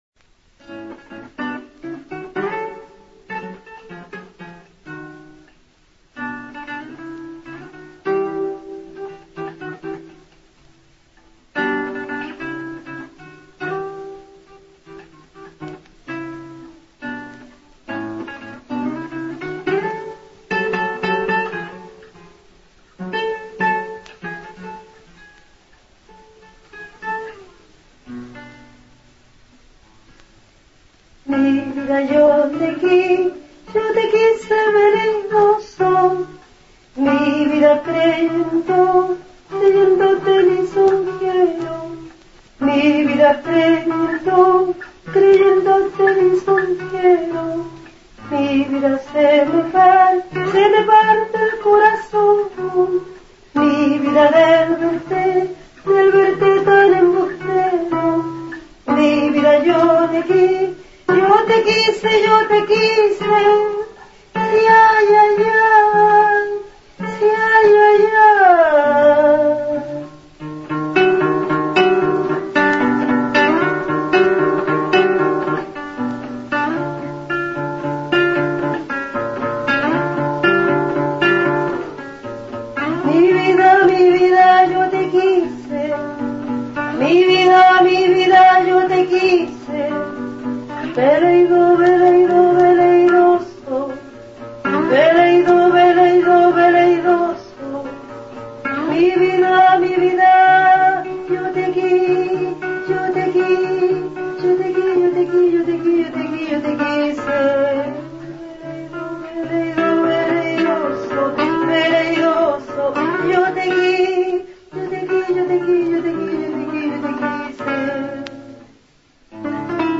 Leímos fragmentos de las "Décimas", su autobiografía en versos. Además hicimos un repaso de sus canciones más emblemáticas.